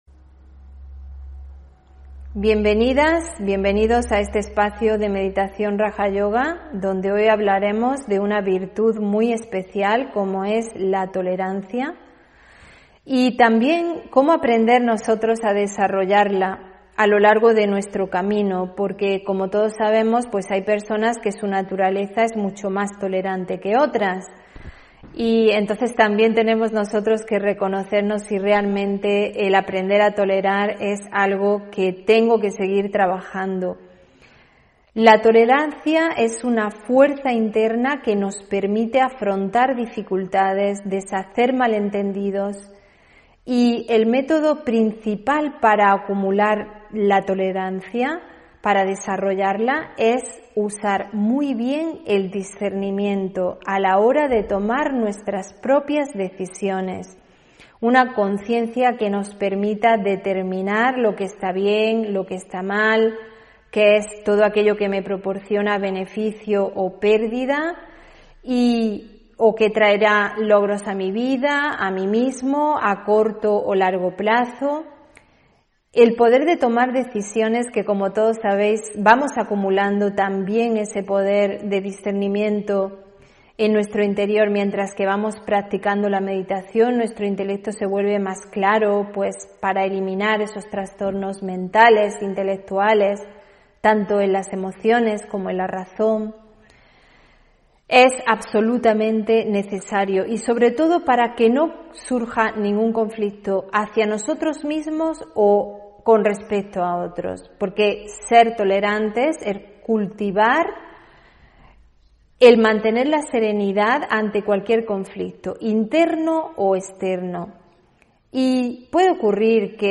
Meditación y conferencia: Desarrollar tolerancia (30 Noviembre 2021)